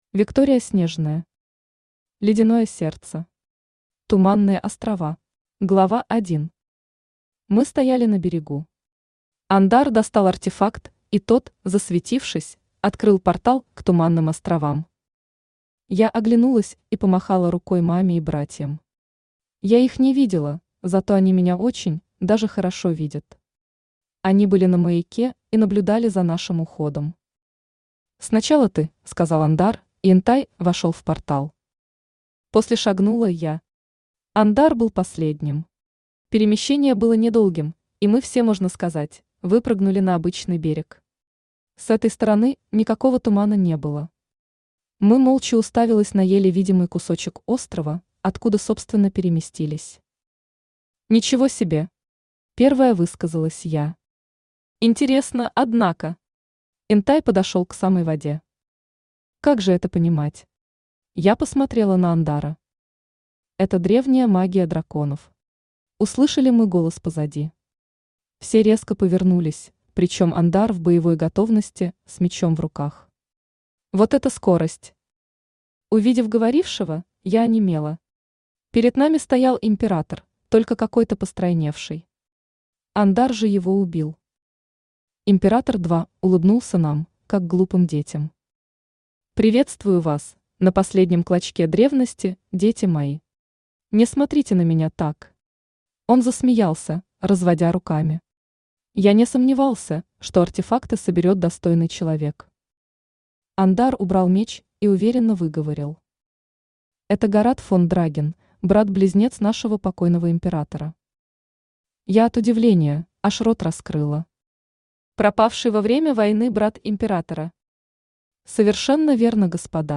Аудиокнига Ледяное сердце. Туманные острова.
Автор Виктория Снежная Читает аудиокнигу Авточтец ЛитРес.